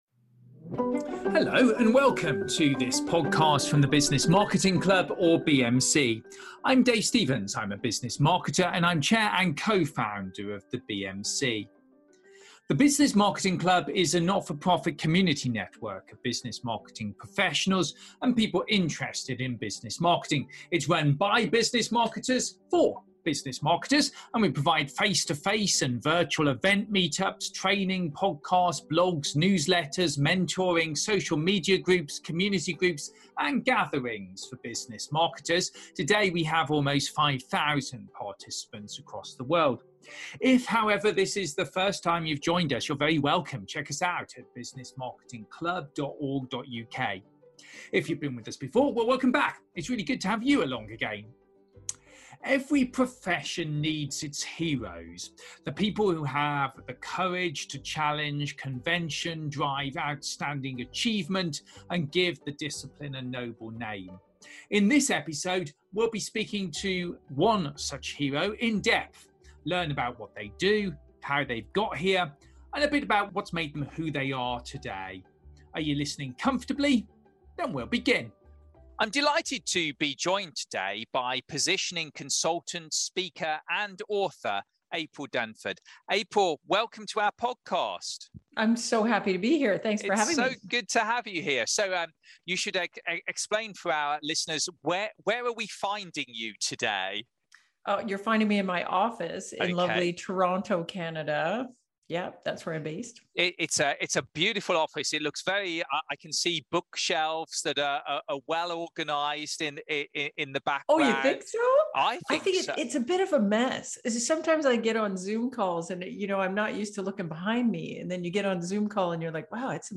Beginning a series of in-depth interviews with some of Business Marketing’s heroes.